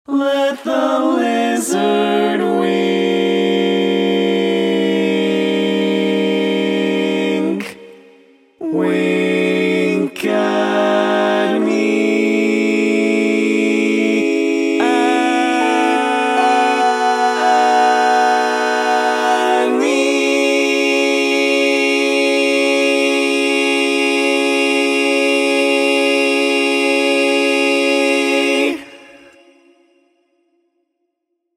Key written in: C# Major
How many parts: 4
Type: Barbershop
All Parts mix:
Learning tracks sung by